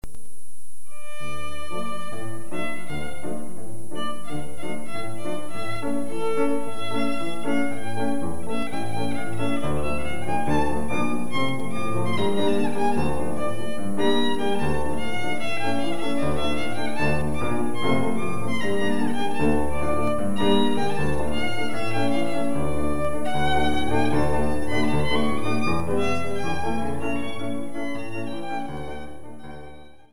- folklore russe: